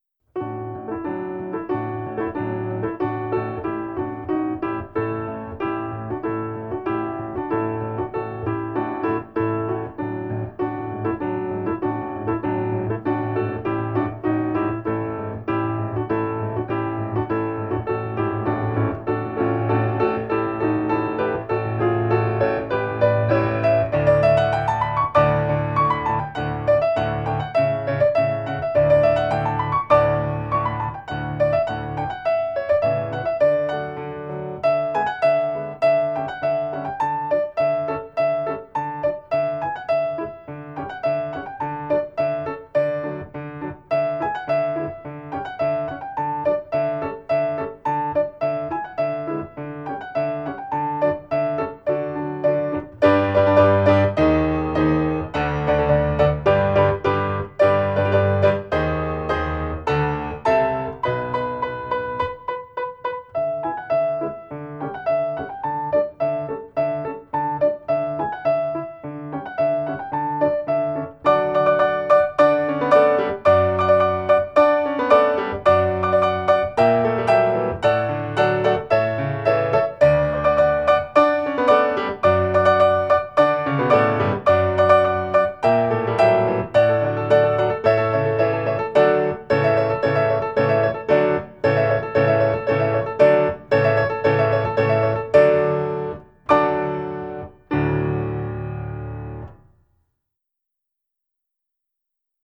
14_Che vuol dir codesta sonata_base
14_Che-vuol-dir-codesta-sonata_base.mp3